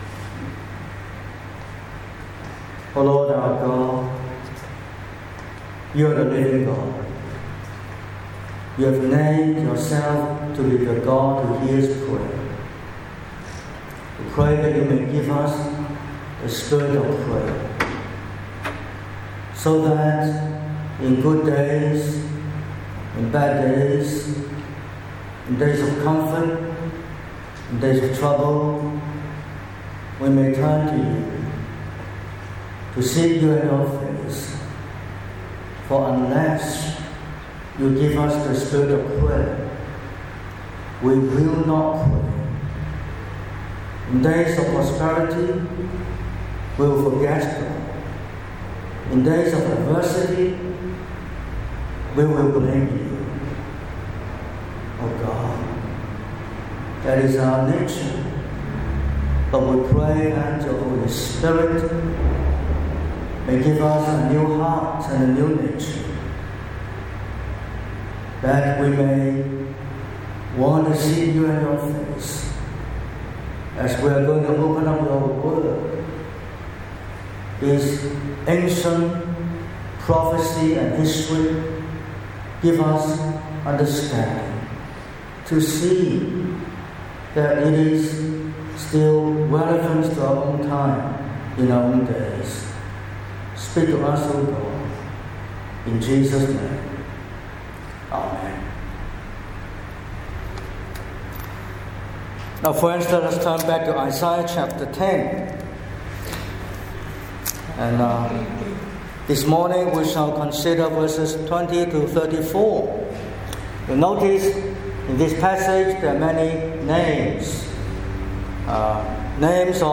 07/12/2025 – Morning Service: The remnant will return